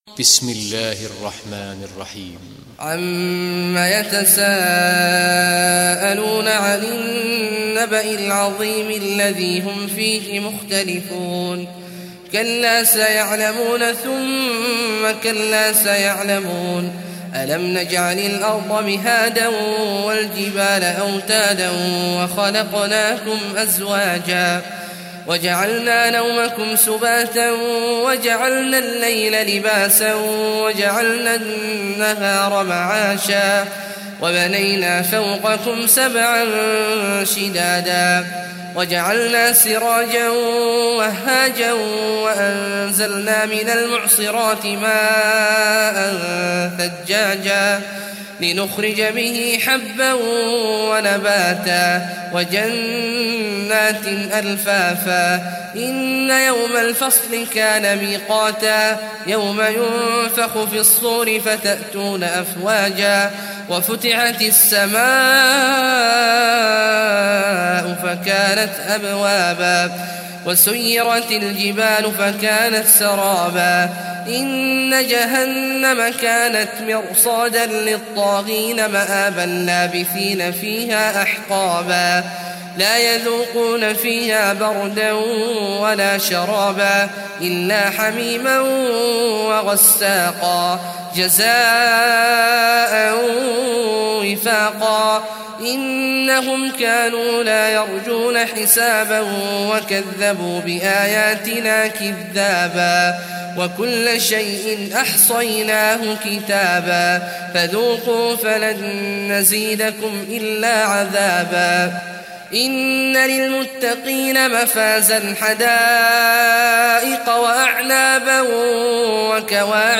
Surah An-Naba Recitation by Sheikh Awad al Juhany
Surah An-Naba, listen online mp3 tilawat / recitation in Arabic in the beautiful voice of Sheikh Abdullah Awad al Juhany.